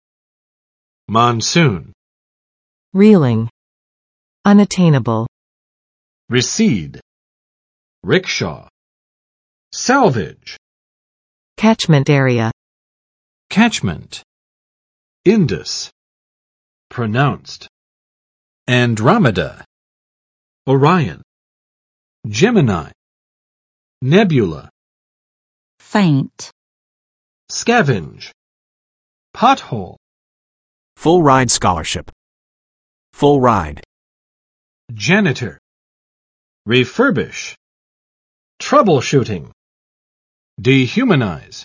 [mɑnˋsun] n.（印度的）季风；雨季